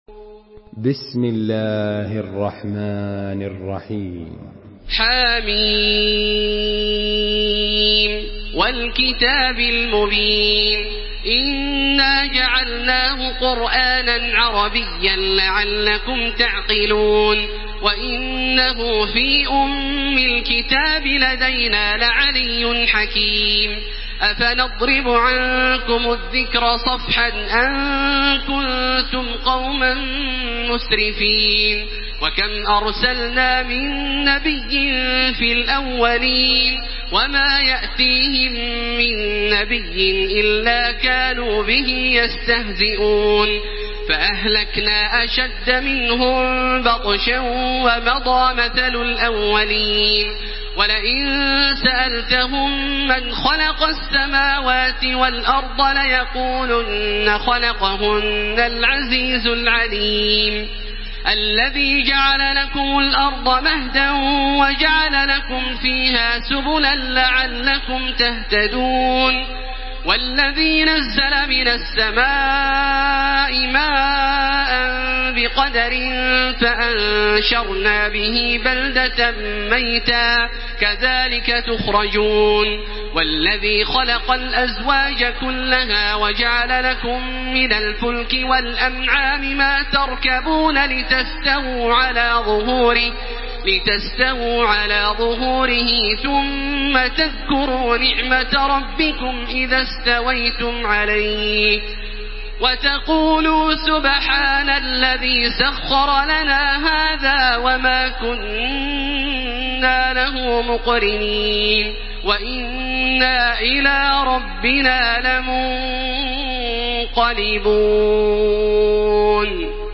Surah Az-Zukhruf MP3 in the Voice of Makkah Taraweeh 1434 in Hafs Narration
Murattal